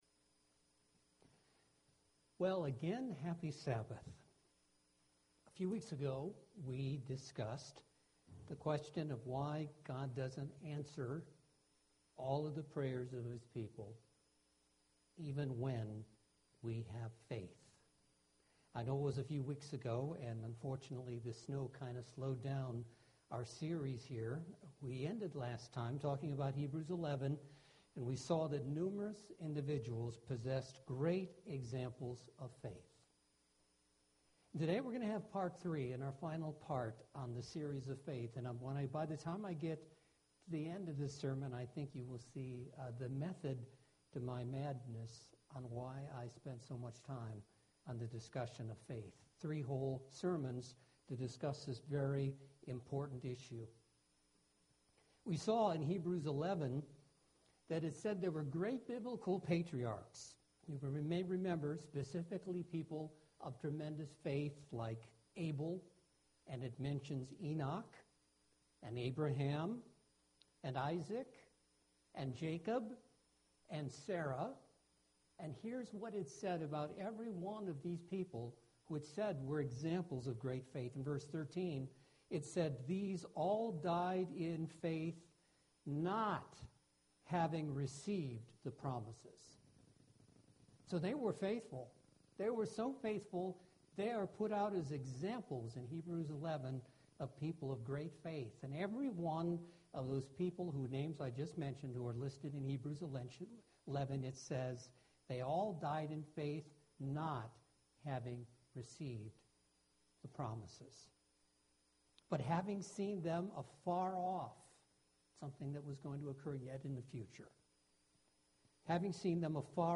This is the final part of a 3-part sermon. In this part, we will look at what might seem like an answer of "no", but is actually an answer of "yes" because God looks at things in the long term.